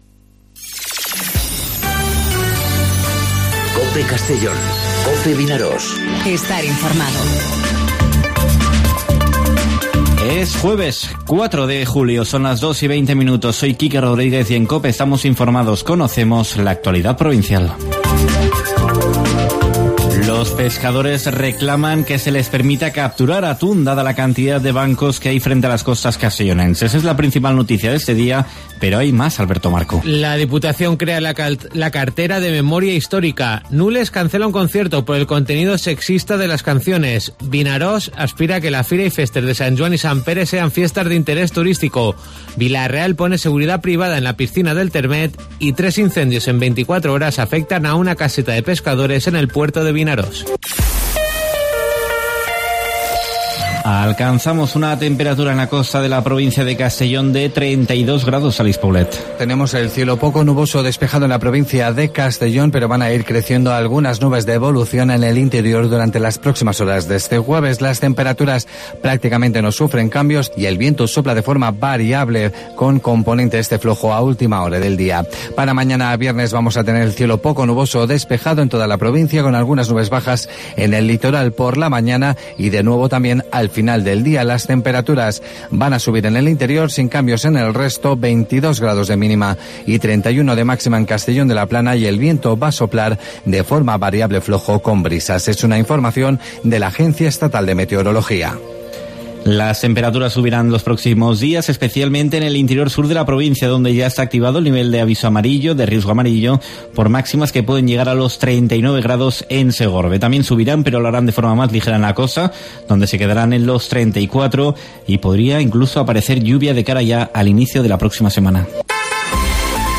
Informativo 'Mediodía COPE' en Castellón (04/07/2019)